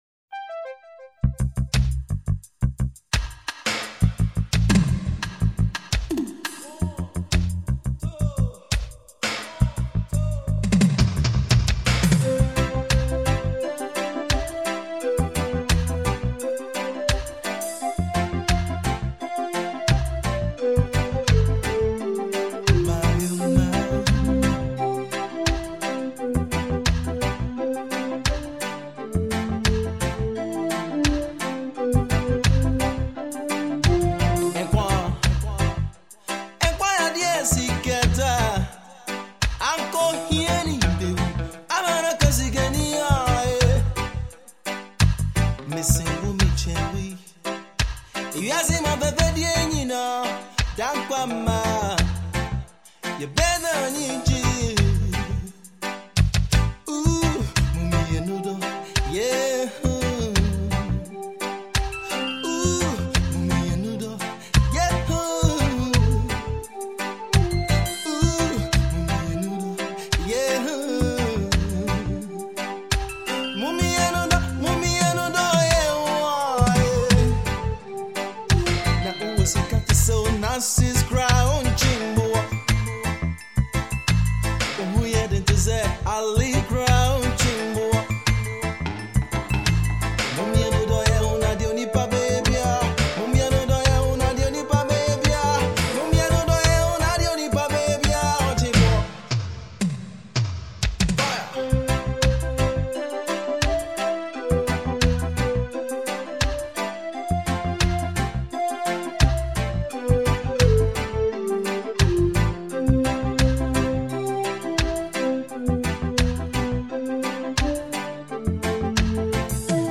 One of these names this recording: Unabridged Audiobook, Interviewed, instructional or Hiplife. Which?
Hiplife